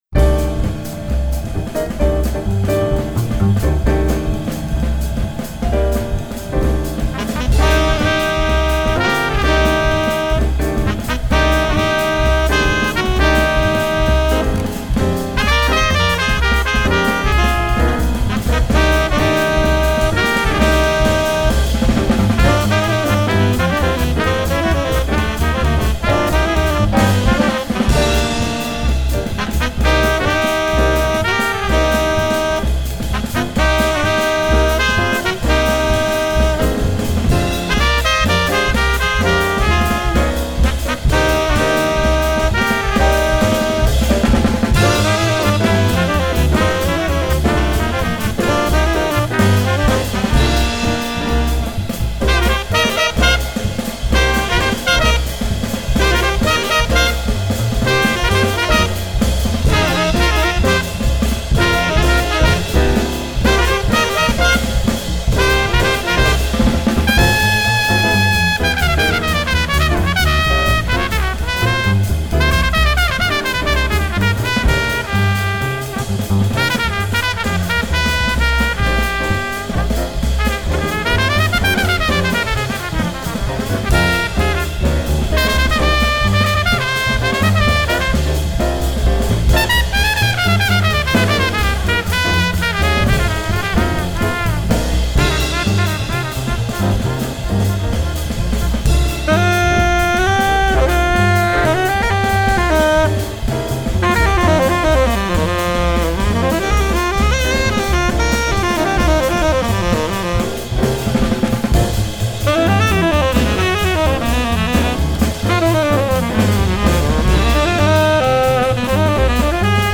jazz album
The musicians and sound quality are wonderful.